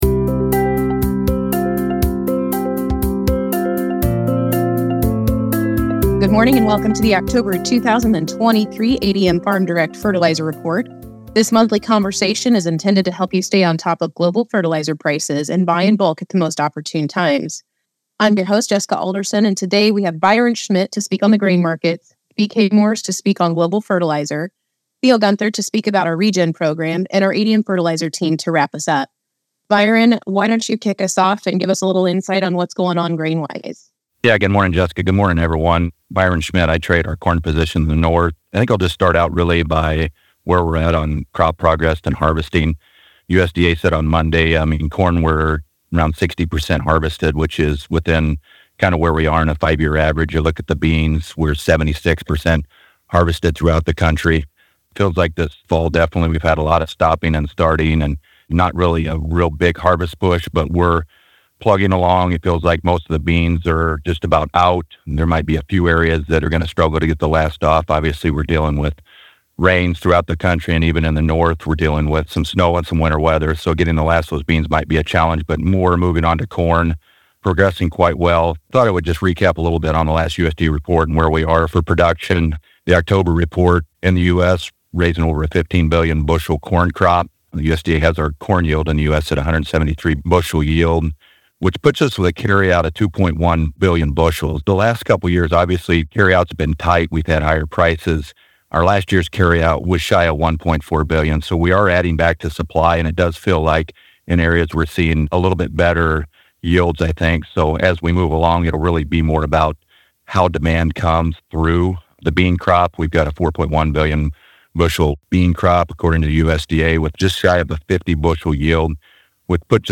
You’ll hear a monthly review of the fertilizer market followed by a grain market update with some Q&A of call-in listeners.